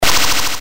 powerup_magnet.mp3